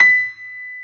55p-pno35-B5.wav